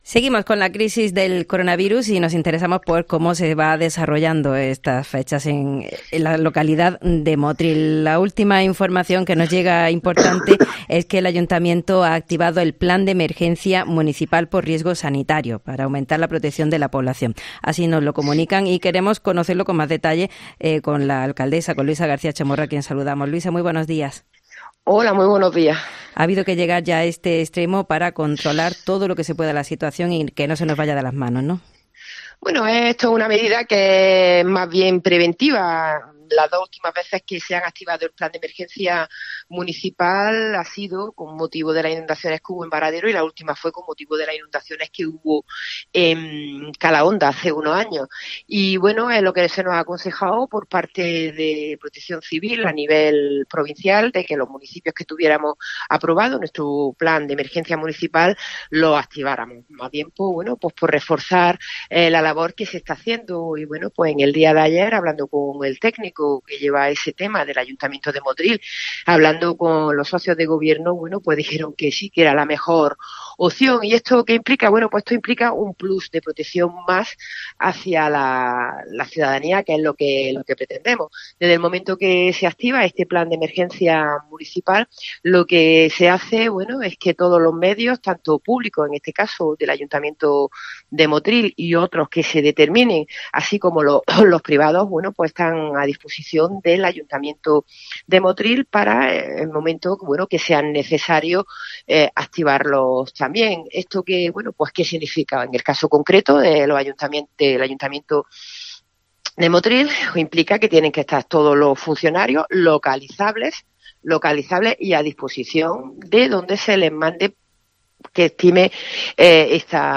La Alcaldesa de Motril, Luisa García Chamorro, además de explicarnos en qué consiste el PLAN DE EMERGENCIA MUNICIPAL , nos cuenta a través de una entrevista, que es una decisión que se toma por previsión, ya que los alcaldes están actuando a ciegas por la política oscurantista que está siguiendo el Gobierno de la Nación, sin querer dar datos sanitarios a nivel local, solo provincial.